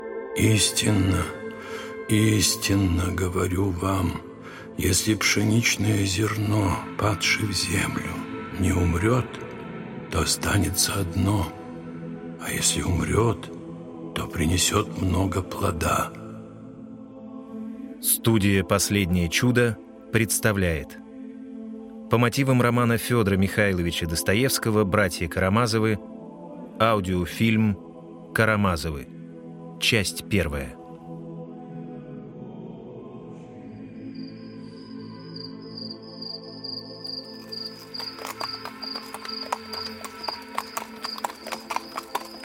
Аудиокнига Карамазовы (спектакль) 1-я серия | Библиотека аудиокниг